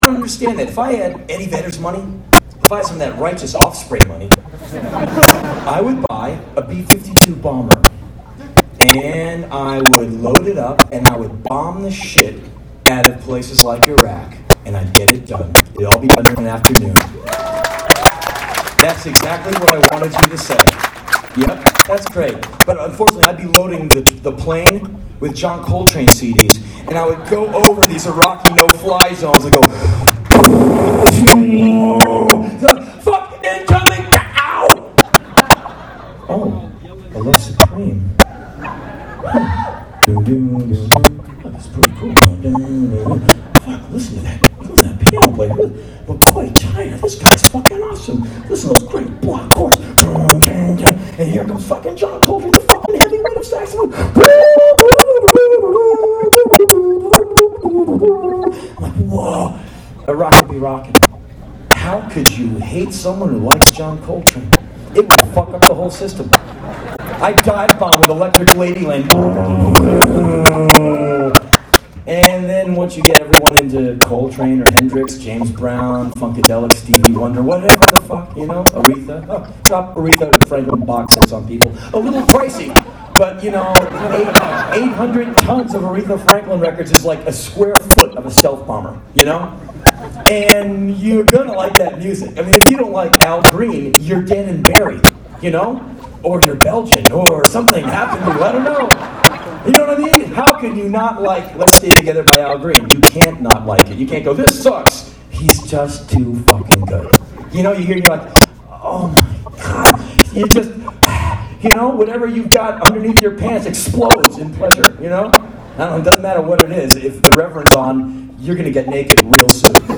this might just be crazy enough to work -- hit 'em with music instead of firepower! Henry Rollins was speaking on tour back in 1999 in relation to other conflicts in the MidEast, but still mightly applicable in my opinion.